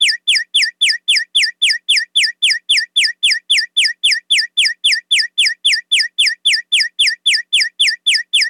Efecto semáforo cuando está verde para los peatones
semáforo
Señales: Tráfico
Sonidos: Ciudad